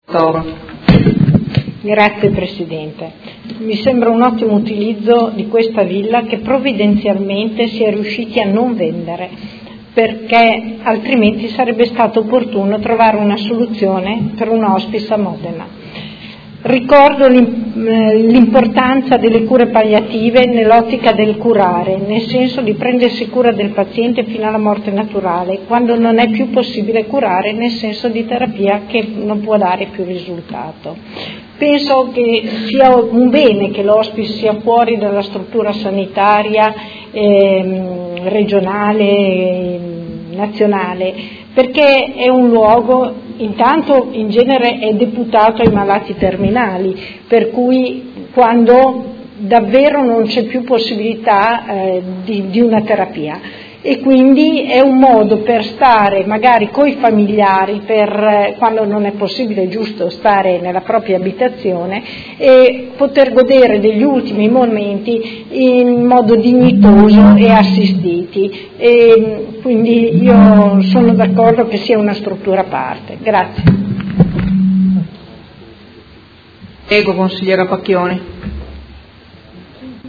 Dibattito su Ordine del Giorno presentato dai Consiglieri Arletti, Fasano, Baracchi, Bortolamasi, De Lillo, Pacchioni, Liotti, Di Padova, Venturelli, Morini e Lenzini (PD) avente per oggetto: Anche Modena si merita un hospice “territoriale” - la programmazione sanitaria preveda una struttura per il territorio modenese analogamente alle altre province della Regione Emilia Romagna ed emendamenti